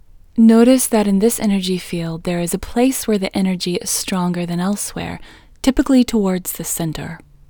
IN – the Second Way – English Female 4